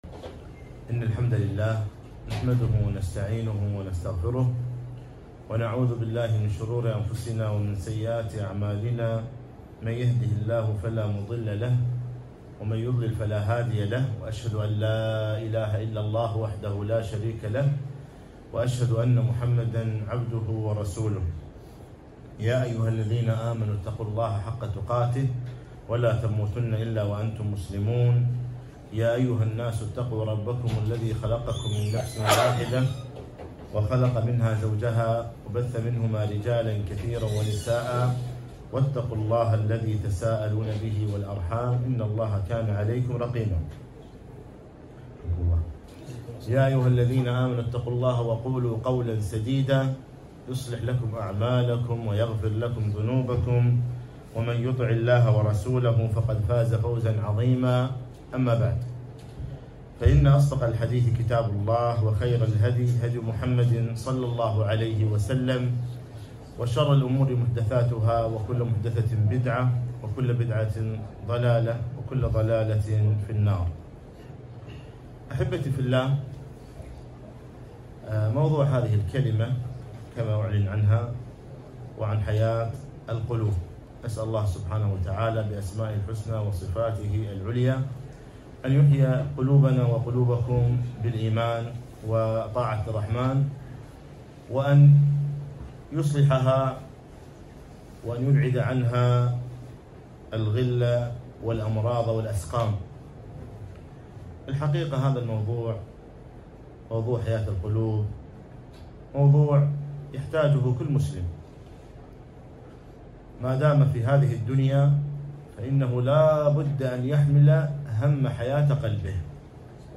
محاضرة - حياة القلوب - دروس الكويت